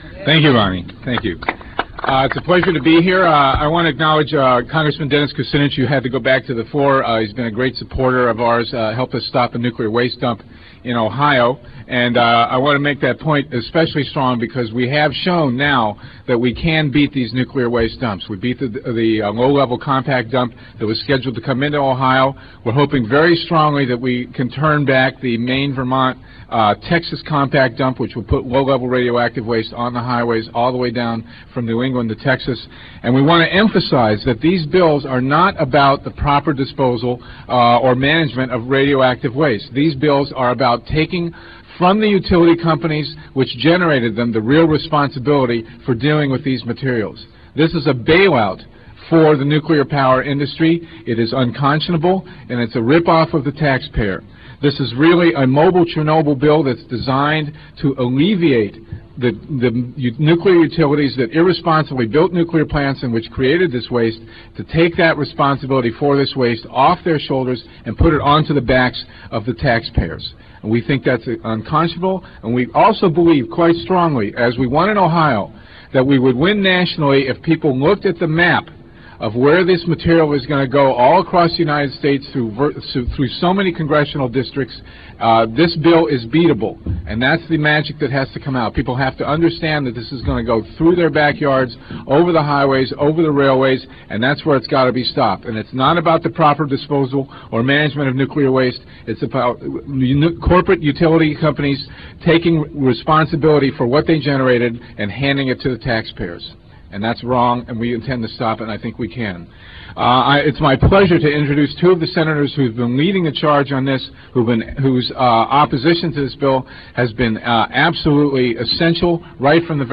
lifeblood: bootlegs: 1997-09-24: honor the earth press conference - washington, d.c.